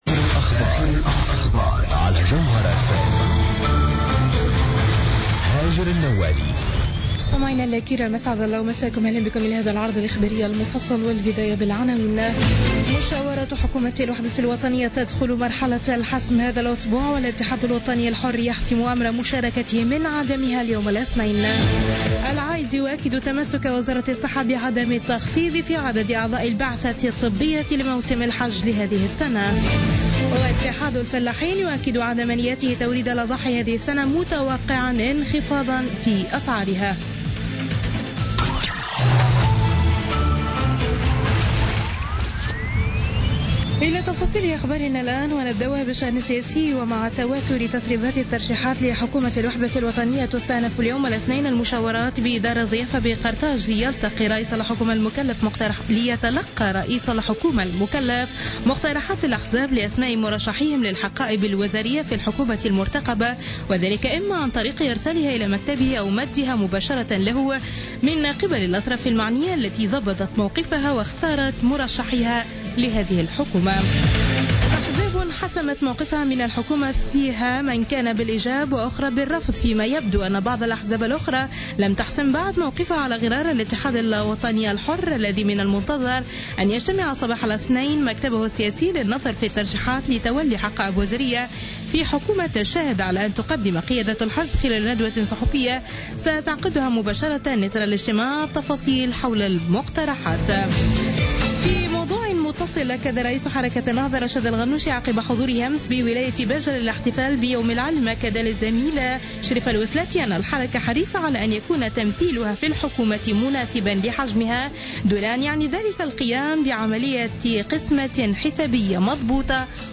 نشرة أخبار منتصف الليل ليوم الإثنين 15 أوت 2016